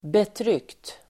Ladda ner uttalet
Uttal: [betr'yk:t]